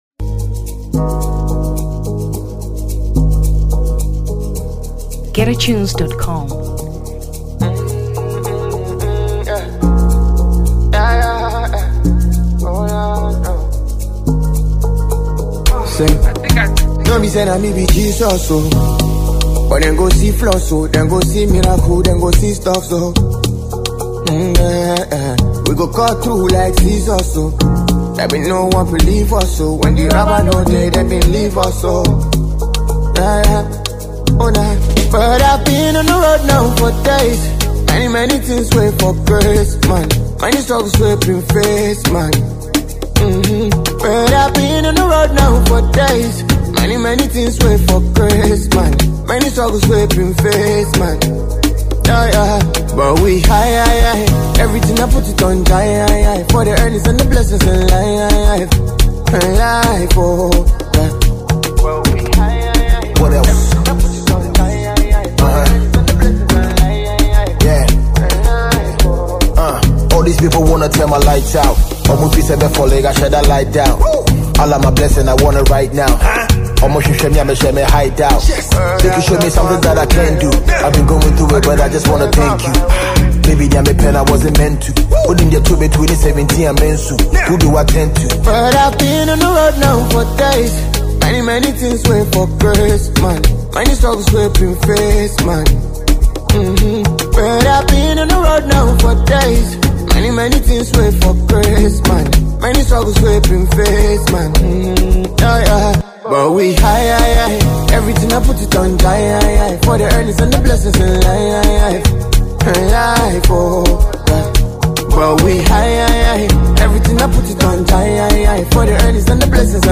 Afrobeats 2023 Ghana